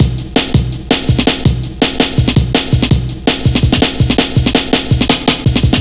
Index of /90_sSampleCDs/Zero-G - Total Drum Bass/Drumloops - 3/track 45 (165bpm)
double garage 2.wav